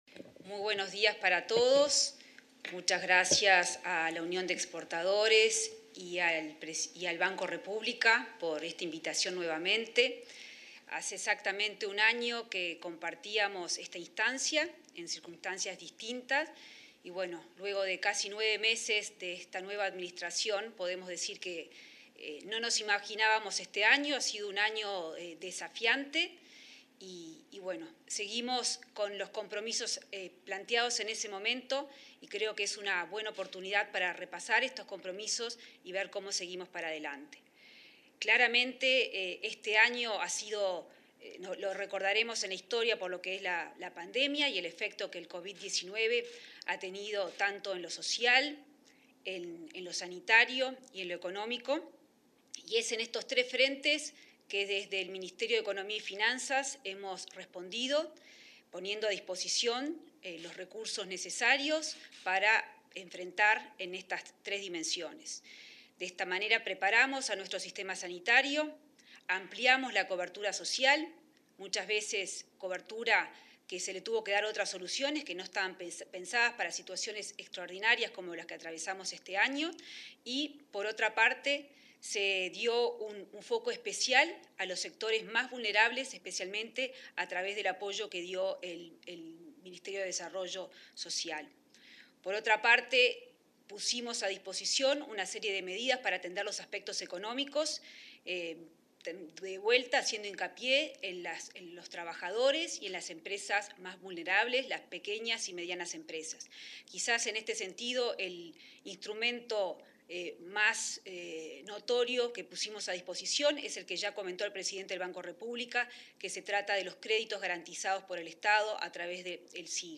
Exposición de la ministra Azucena Arbeleche
Exposición de la ministra Azucena Arbeleche 18/12/2020 Compartir Facebook X Copiar enlace WhatsApp LinkedIn En el marco del Día del Exportador 2020, la ministra de Economía y Finanzas, Azucena Arbeleche, realizó una videoconferencia en Torre Ejecutiva, este viernes 18 de diciembre.